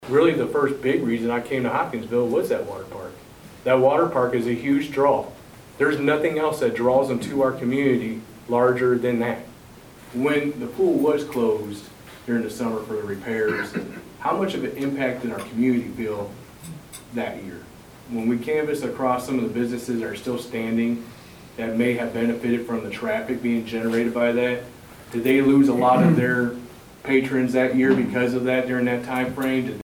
At a town hall Wednesday night, the room may have been full of residents and city officials, but only a handful voiced opinions on Bluegrass Splash, and those were split.